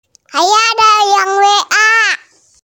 Notification icon Nada dering WA sebut nama suara Google
Kategori: Nada dering